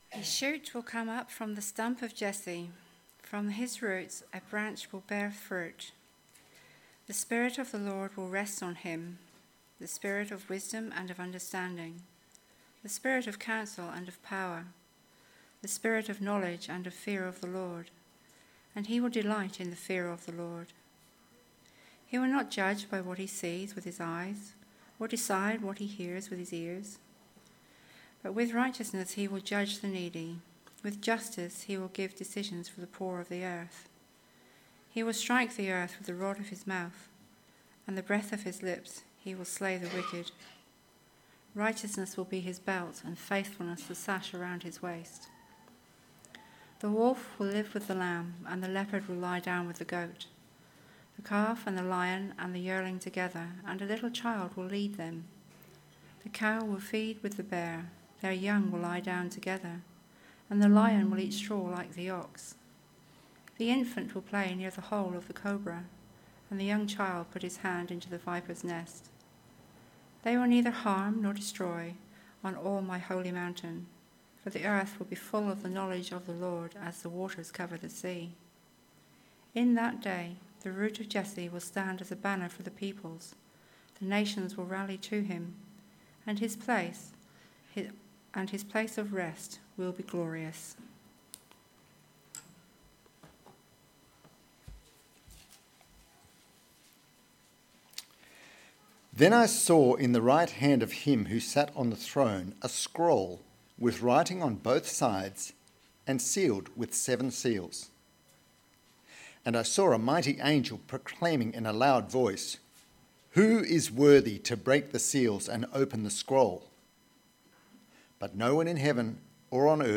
This thought provoking sermon takes a look at some of the things that he saw and what he must have felt. He also explores the cost of the Deed of Purchase for mankind’s possession of the earth.
Service Type: Sunday AM